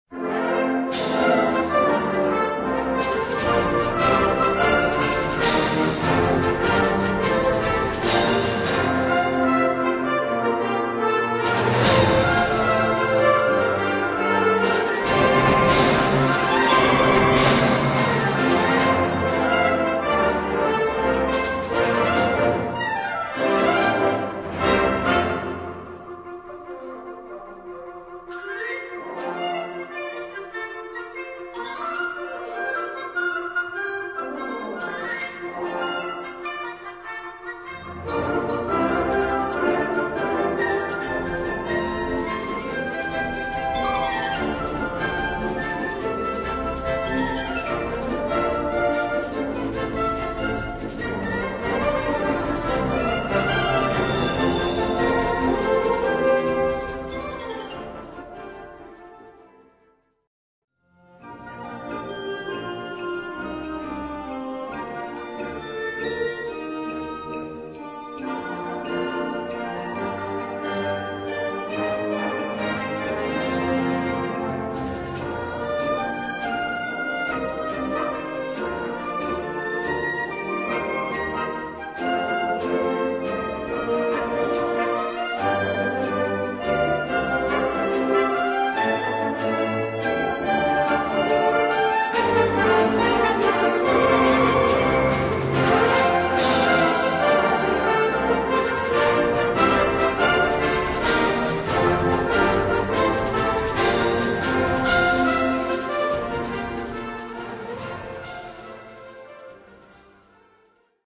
Categoria Concert/wind/brass band
Sottocategoria Musica di apertura, chiusura e bis
Instrumentation Ha (orchestra di strumenti a faito)